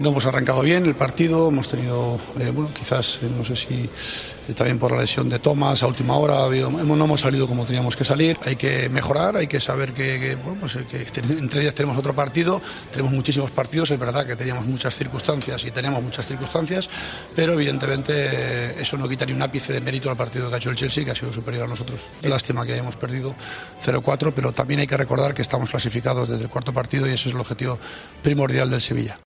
AUDIO: El entrenador del Sevilla ha valorado la derrota de su equipo 0-4 frente al Chelsea.